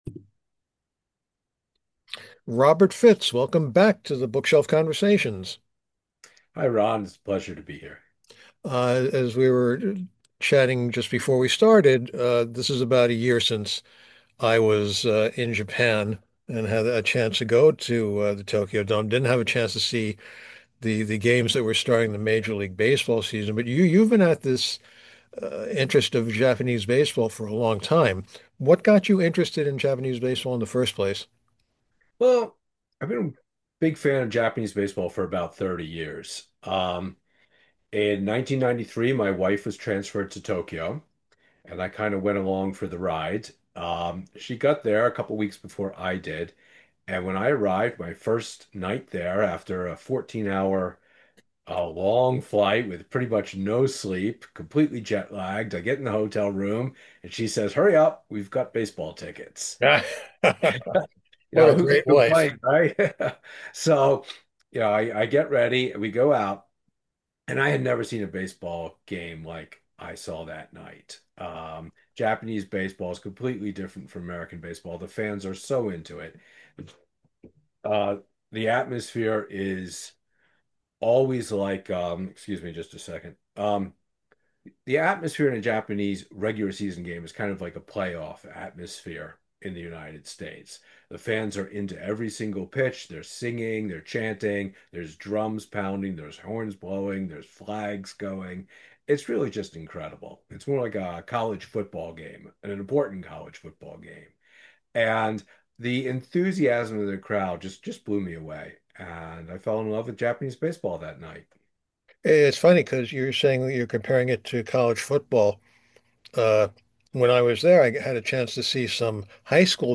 The Bookshelf Conversations #203